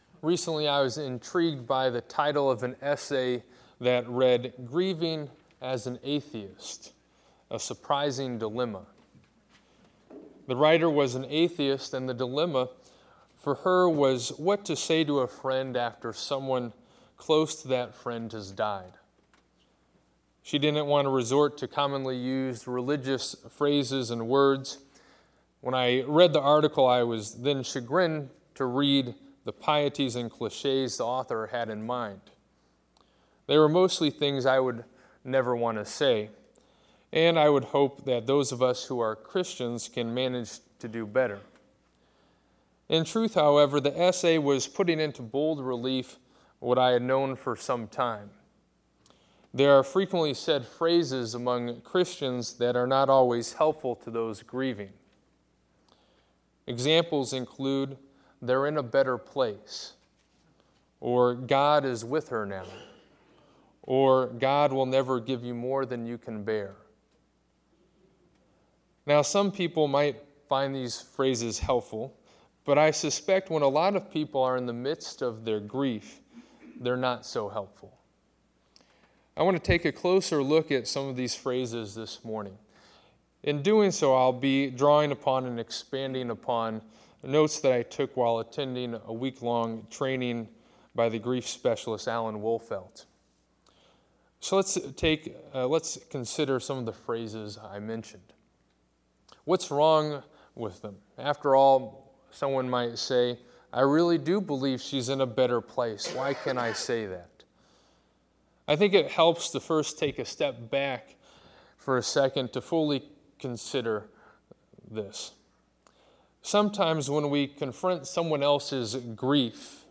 To listen to this sermon about what to say and not say to those in grief, click here.